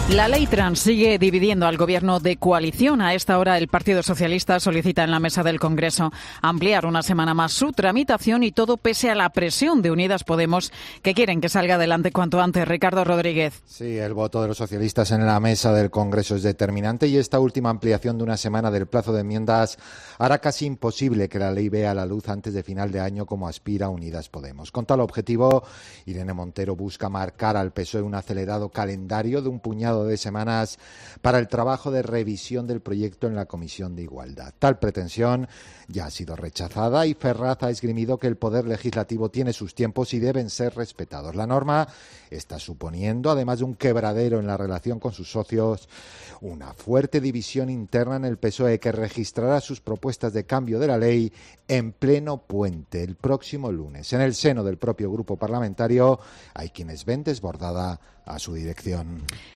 El PSOE pide una nueva prórrogra para la Ley Trans e irrita a Irene Montero. Crónica